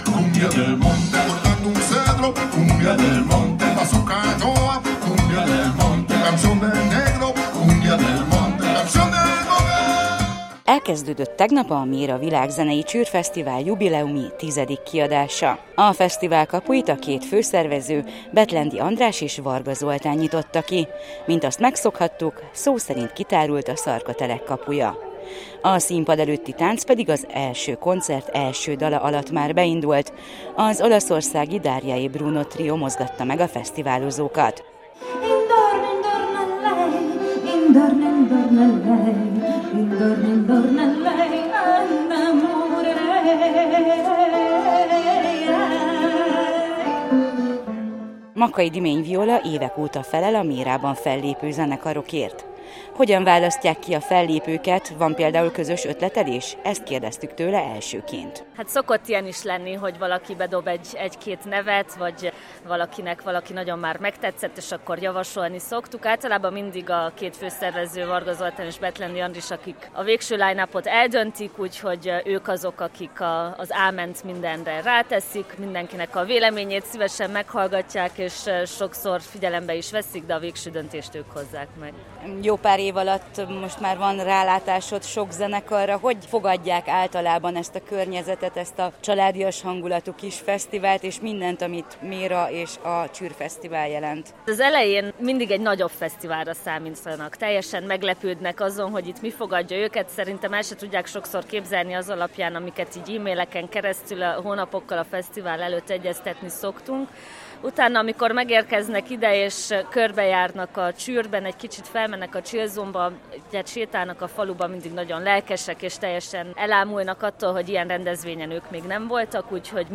Ott voltunk a Méra Világzenei Csűrfesztivál első napján.